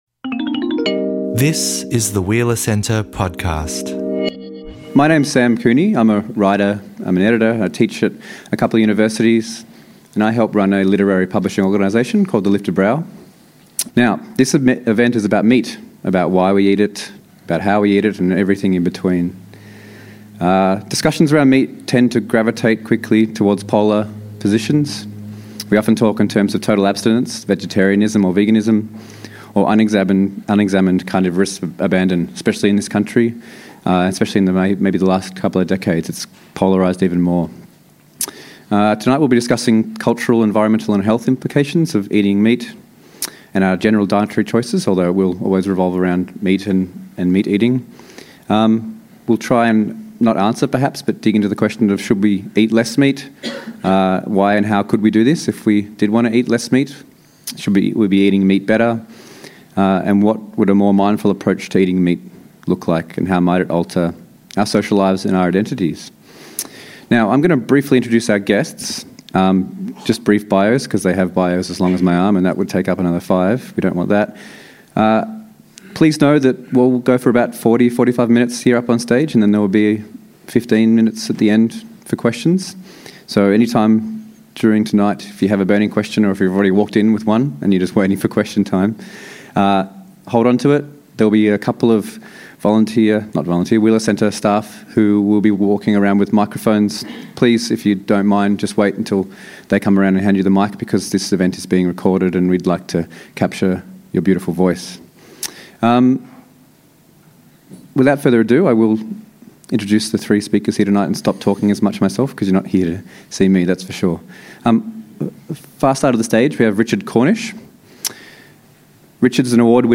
Join us for a nuanced discussion about an issue that cuts close to the bone.